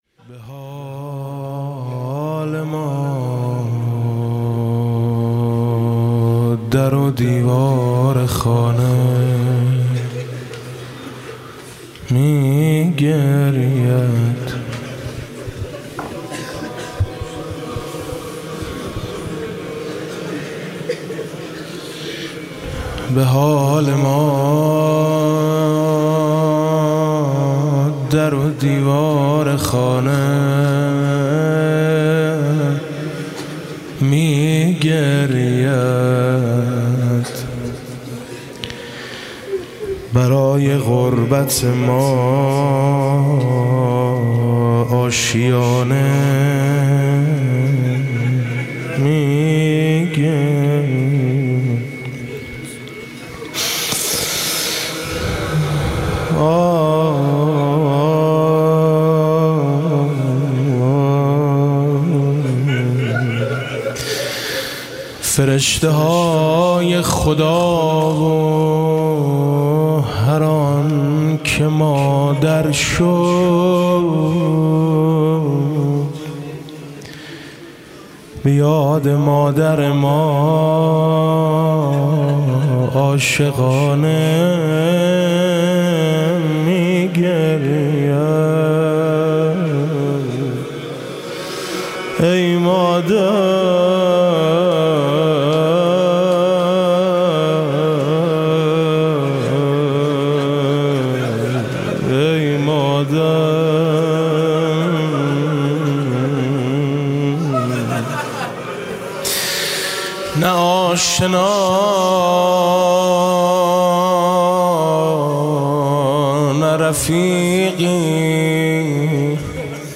3 بهمن 95 - روضه - وقتی که باغ می سوخت ...